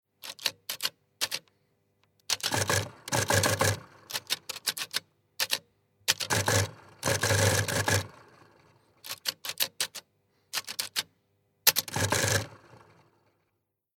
Elektrischer Tischrechner "Logos 58"
Tippen
0149_Tippen.mp3